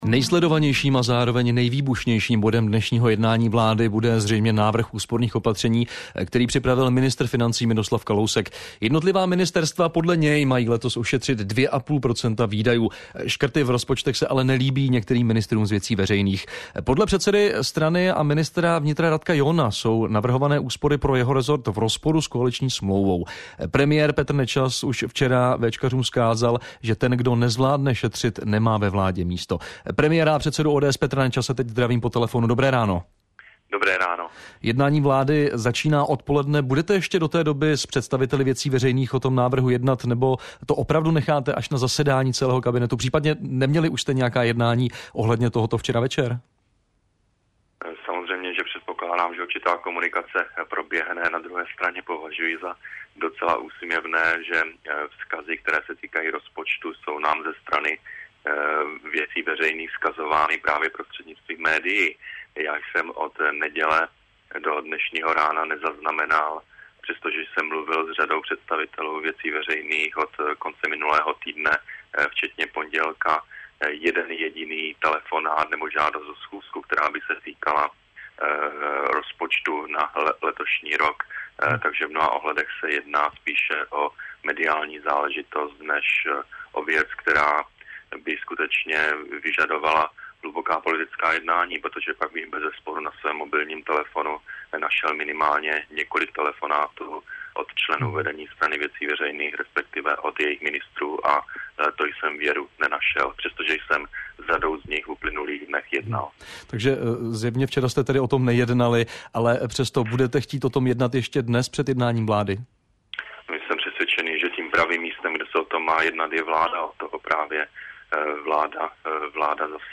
„Každý občan nechť posoudí, zda ve své domácnosti poté, co odečte povinné výdaje typu náklady na bydlení, na svícení, na hypotéku a podobně, dokáže nebo nedokáže ušetřit 2,5 procenta svých výdajů. Jsem přesvědčen, že každá česká domácnost dokáže, je-li to nutné, ušetřit 2,5 procenta ze svých výdajů. Musí to dokázat i každé české ministerstvo,“ řekl premiér v Českém rozhlase.
01_CRo1-Radiozurnal_rozhovor-s-PN.mp3